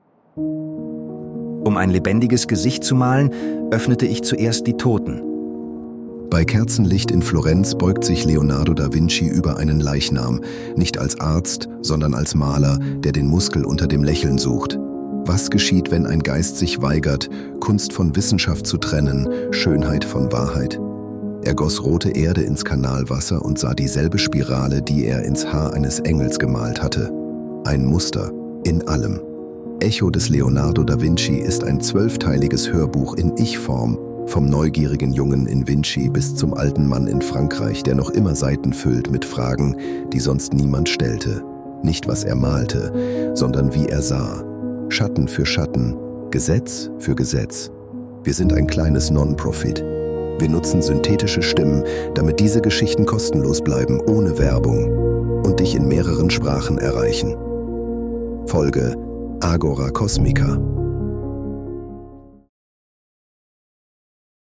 Wir nutzen synthetische Stimmen, damit diese Geschichten kostenlos